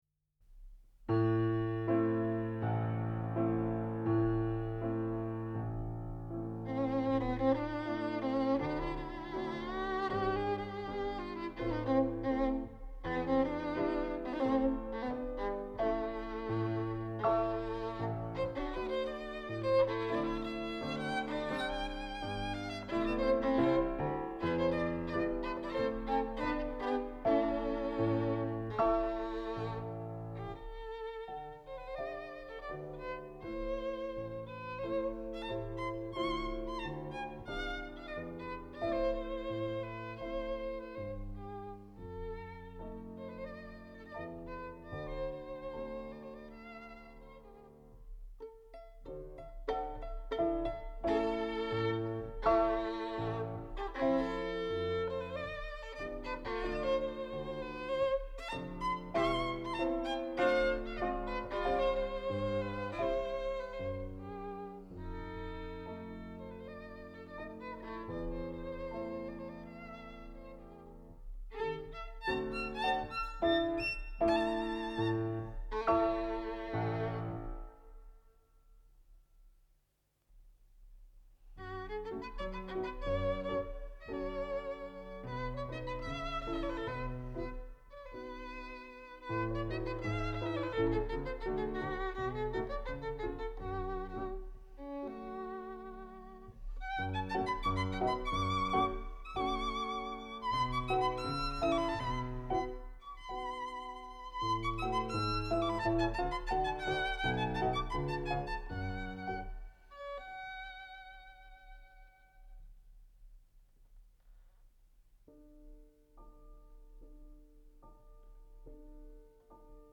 Suita de șase piese compuse de Béla Bartók, având ca baza melodii transilvanene pentru vioara
la pian. Înregistrare pe banda magnetica (C 53670), inclusa în Arhiva Radio România în 1978.